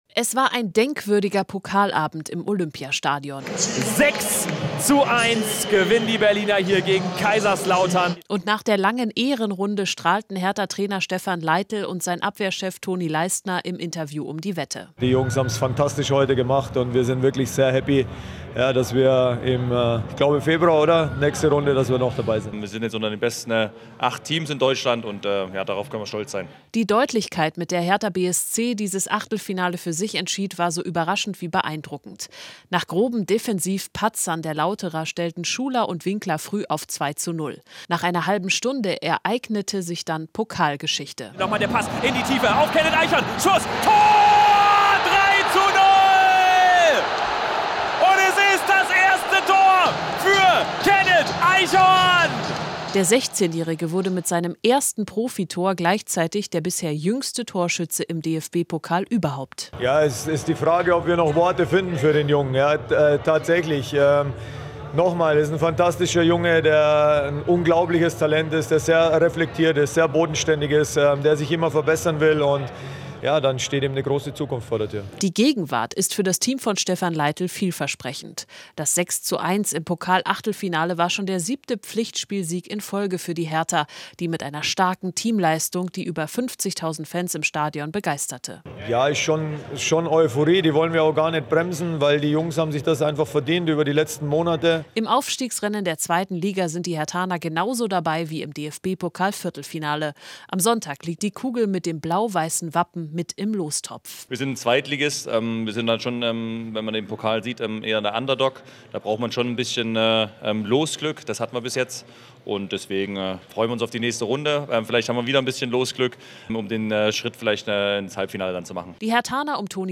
In Interviews und Reportagen blicken wir auf den Sport in der Region und in der Welt.